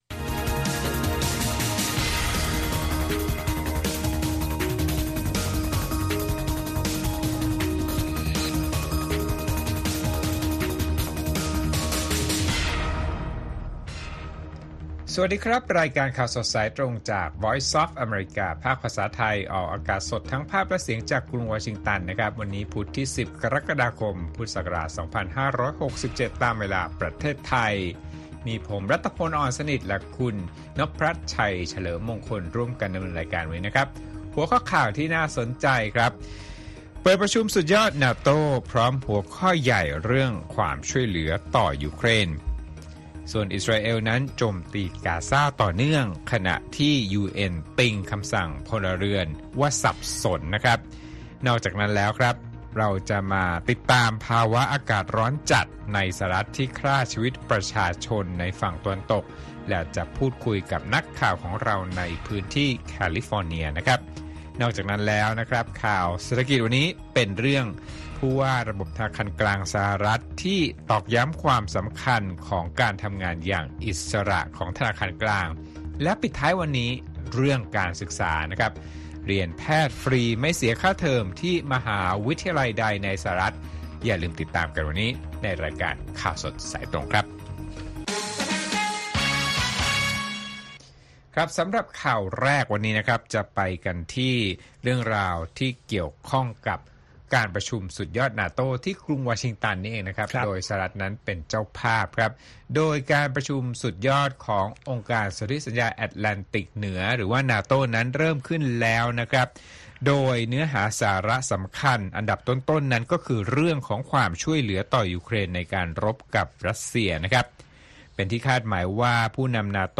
ข่าวสดสายตรงจากวีโอเอ ไทย ประจำวันที่ 10 กรกฎาคม 2567